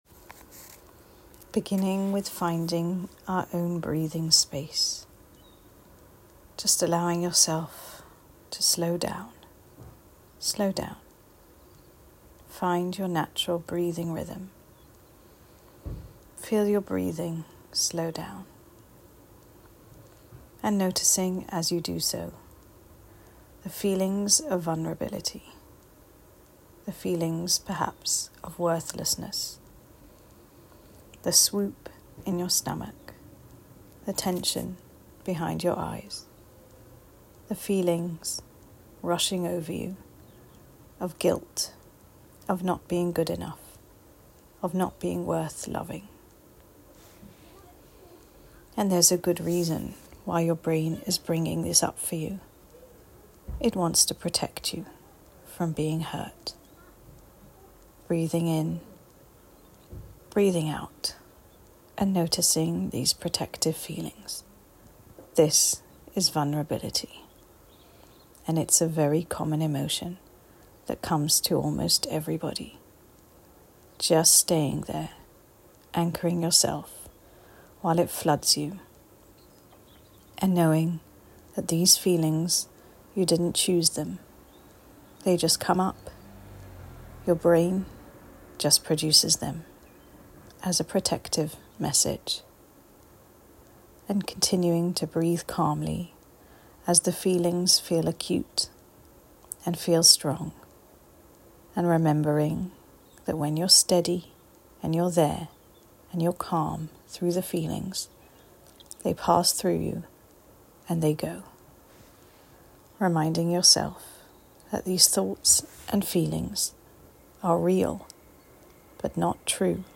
Enjoy and feel free to share TorahPsych’s collection of breathing and visualisation exercises to help you calmly tolerate feelings.